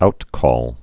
(outkôl)